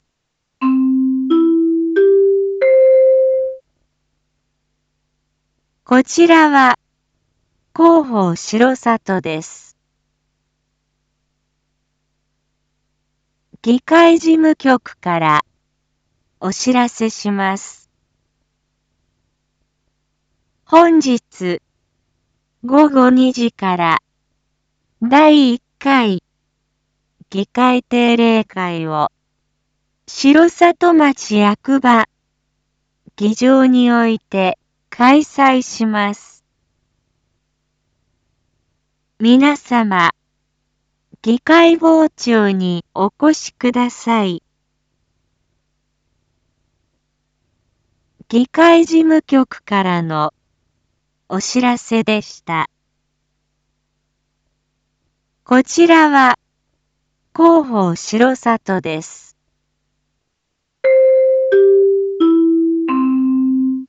BO-SAI navi Back Home 一般放送情報 音声放送 再生 一般放送情報 登録日時：2024-03-15 07:01:10 タイトル：第１回議会定例会⑧ インフォメーション：こちらは広報しろさとです。